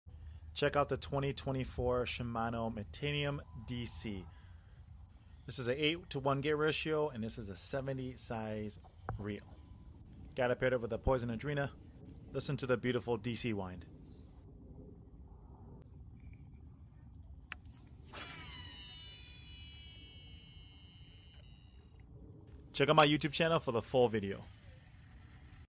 2024 Shimano Metanium on the water shot! Check out thag beautiful DC sound!